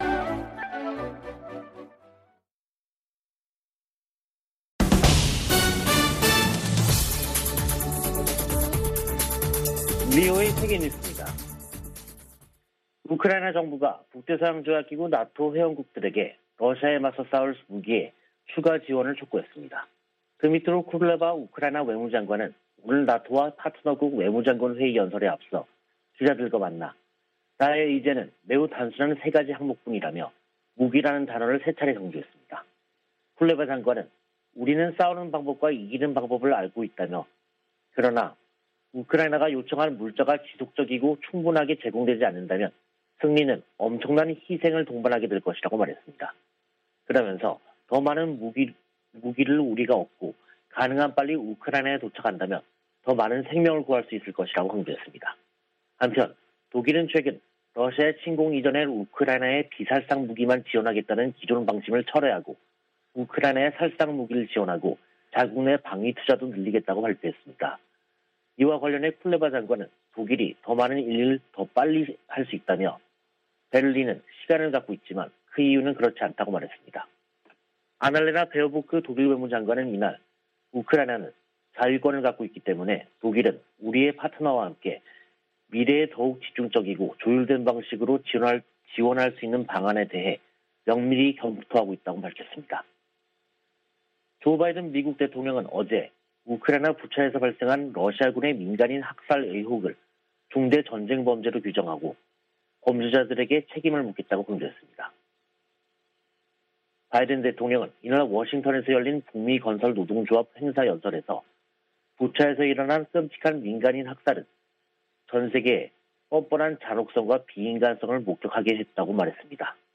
VOA 한국어 간판 뉴스 프로그램 '뉴스 투데이', 2022년 4월 7일 3부 방송입니다. 웬디 셔먼 미 국무부 부장관은 핵무장한 북한은 중국의 이익에도 부합하지 않는다며, 방지하기 위한 중국의 협력을 촉구했습니다. 미 국방부는 한국 내 전략자산 배치와 관련해 한국과 협력할 것이라고 밝혔습니다. 윤석열 한국 대통령 당선인이 캠프 험프리스를 방문해 북한의 핵과 미사일 위협에 강력 대응 의지를 밝혔습니다.